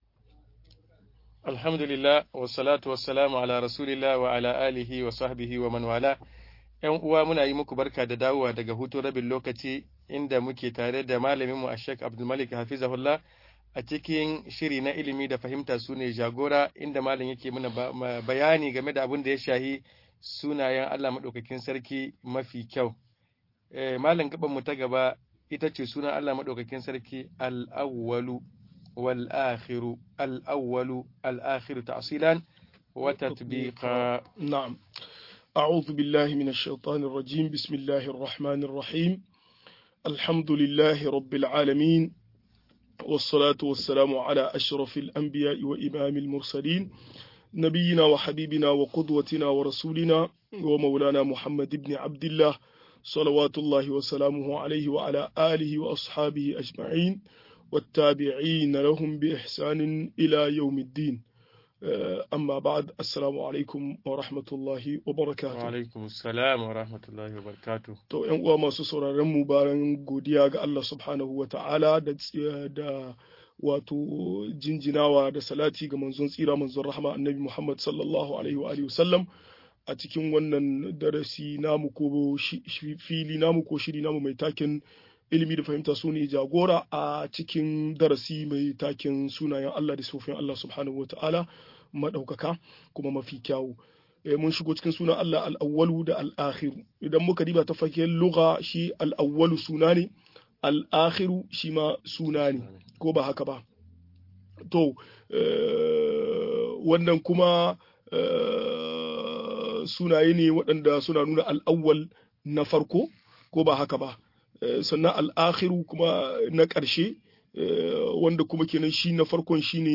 Sunayen Allah da siffofin sa-20 - MUHADARA